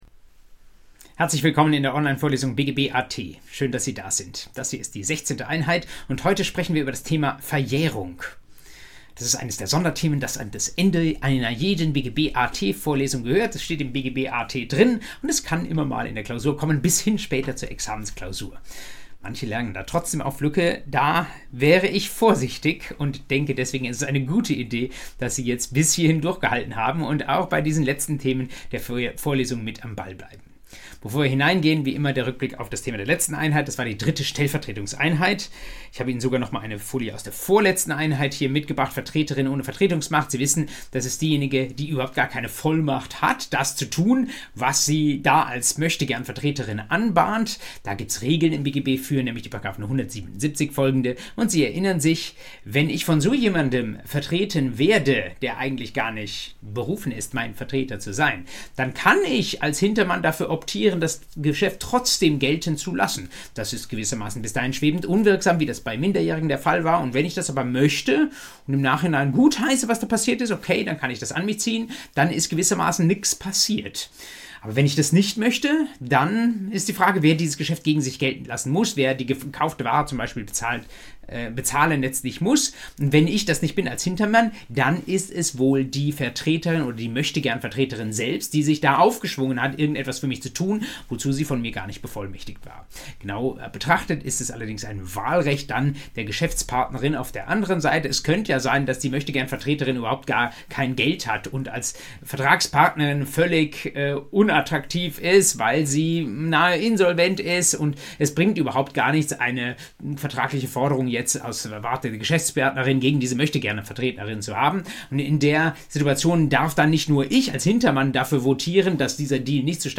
BGB AT Folge 16: Verjährung ~ Vorlesung BGB AT Podcast